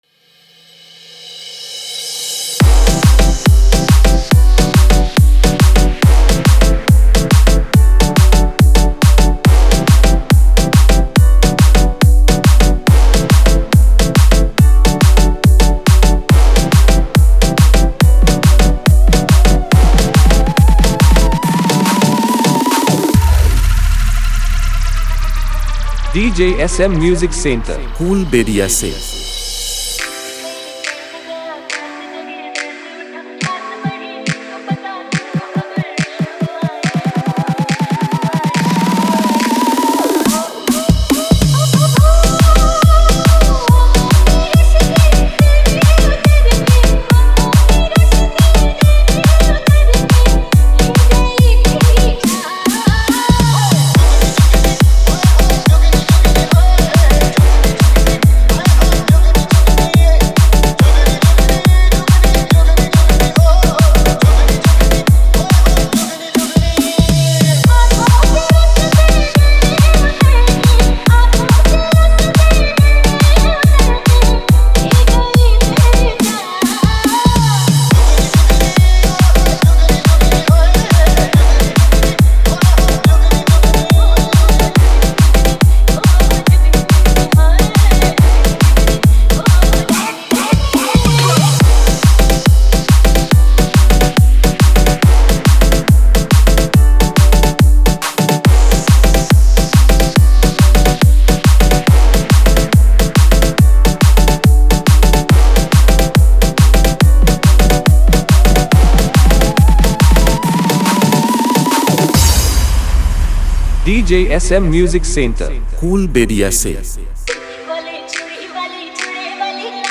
Hindi New Style Edm Bass Mix 2024
High Bass Music